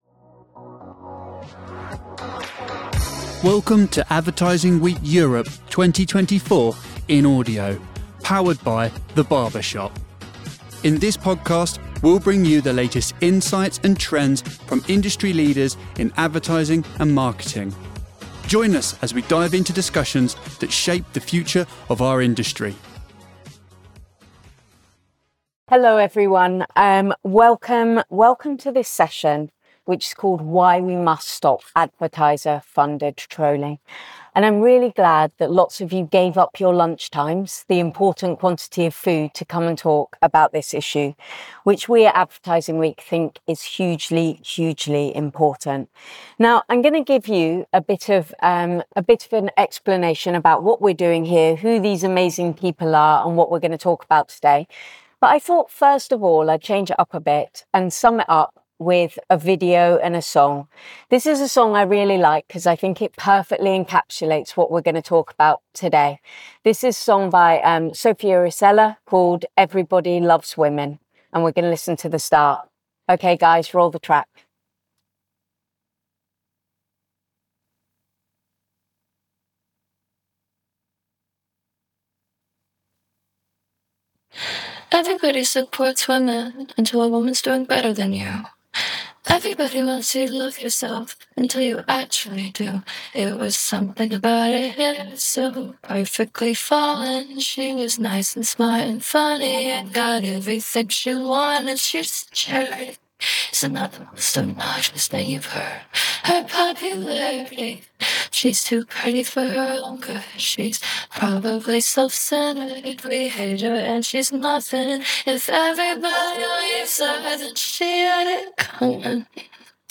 Yet much trolling takes place on sites which are supported by major advertisers. This session investigates how the ad industry can make sure it is not funding hate.